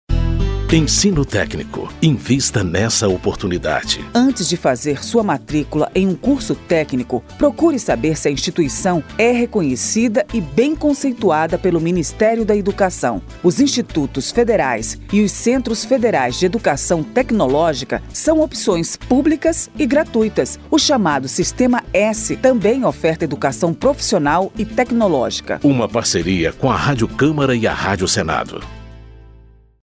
Por isso, a Rádio Câmara e a Rádio Senado criaram cinco spots com informações sobre educação técnica e profissional, mostrando o valor das carreiras técnicas e incentivando o investimento nesse setor.